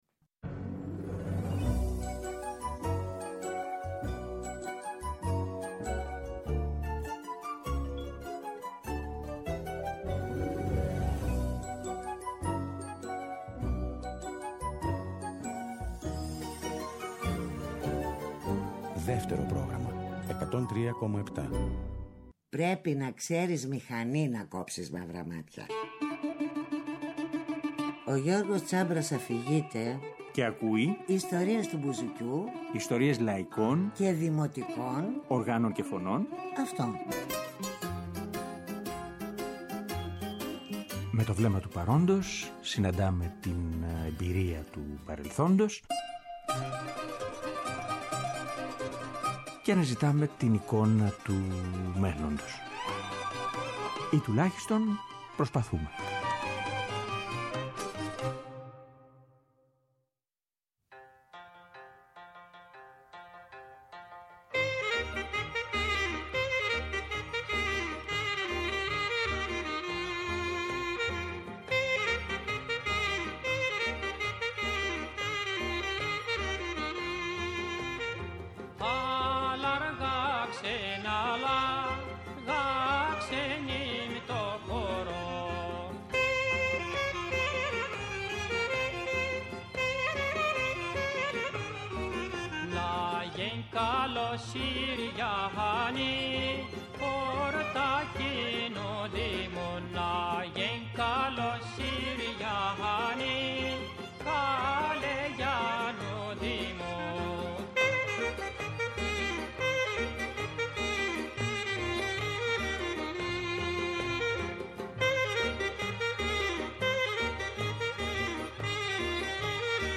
Μιλάμε στο τηλέφωνο με την Ξανθίππη Καραθανάση, για την συνεργασία τους τότε και στην δισκογραφία αλλά και στο ραδιόφωνο και σε συναυλίες στην Ελλάδα και στο εξωτερικό.
Ανατρέχουμε λοιπόν σε μια σειρά ηχογραφήσεων μικρών δίσκων στα πρώτα χρόνια του ’60. Με δημοτικά τραγούδια από διάφορες περιοχές της Ελλάδας.